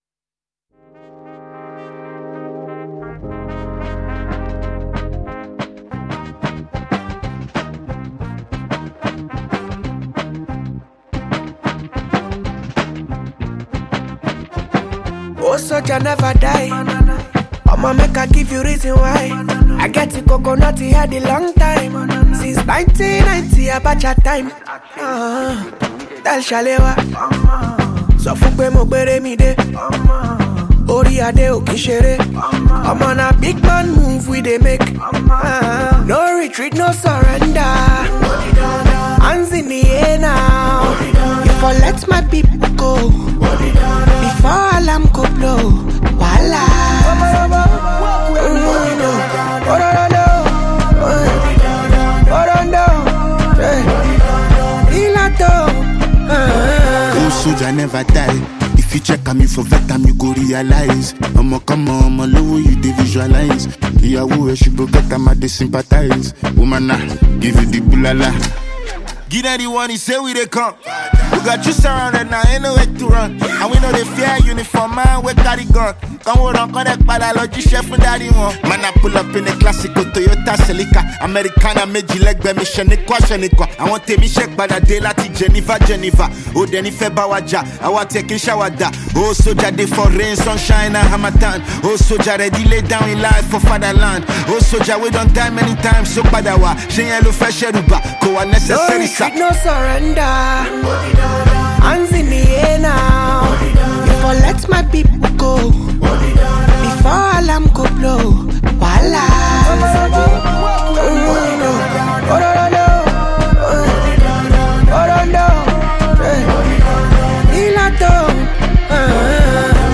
Nigerian rapper and singer
offering a mix of Afrobeat, hip-hop, and highlife sounds.